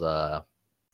Uhm 03